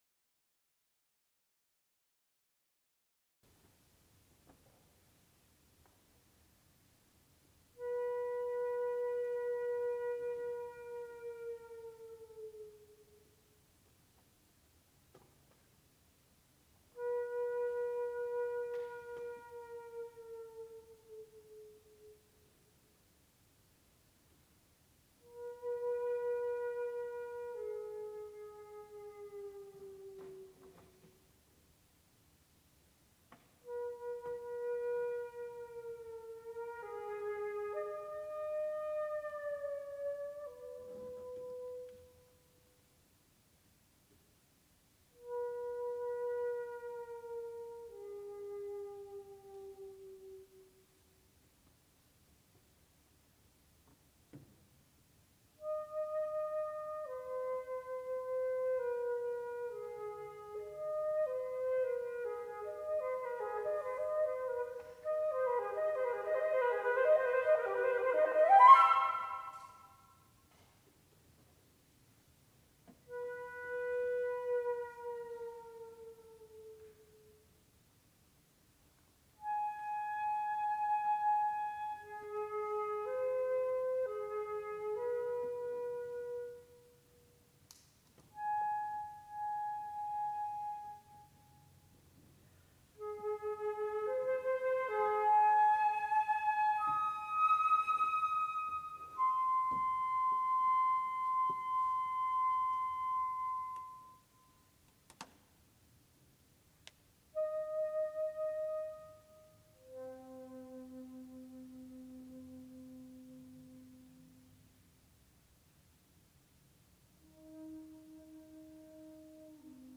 Flute
World Premiere Live Performance in England